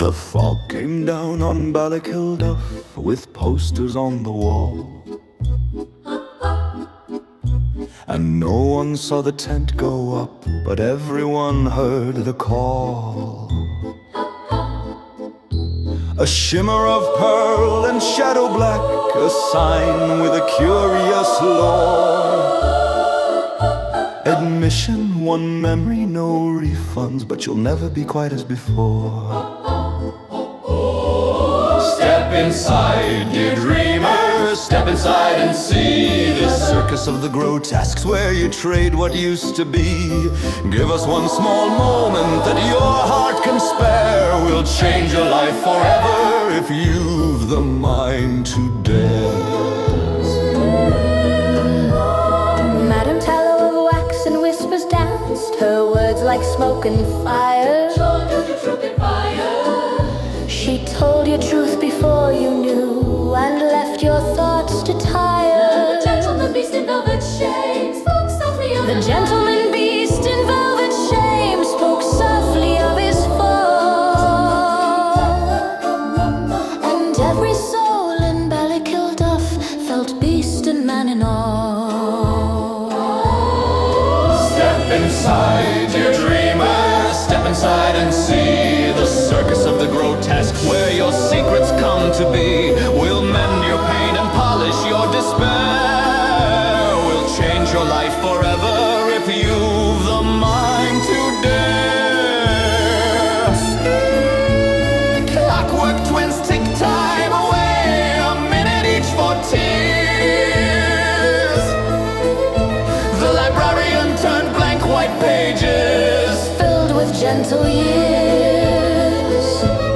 (A song for Doctor Vaude and the people of Ballykillduff)